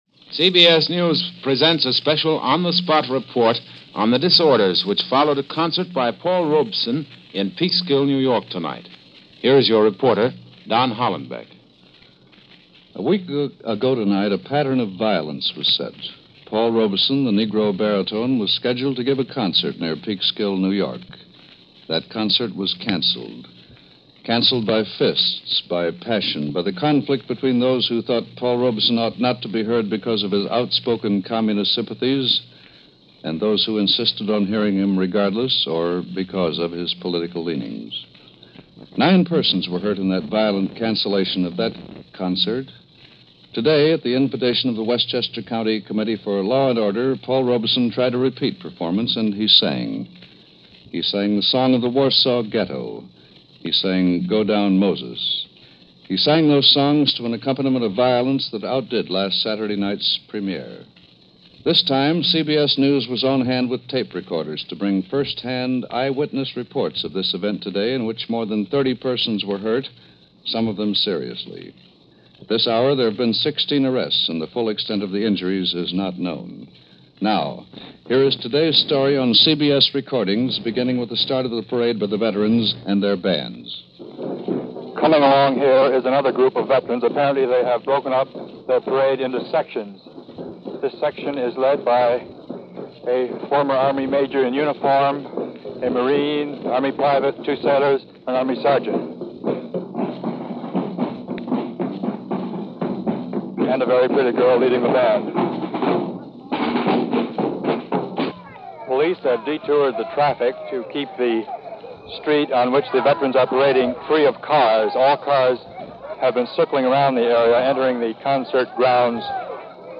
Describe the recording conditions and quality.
In September 4th 1949, CBS Radio ran a documentary on the goings on in Peekskill, with on-the-spot reports and a recap of the events surrounding what became yet another in an ugly legacy of small-mindedness and hate.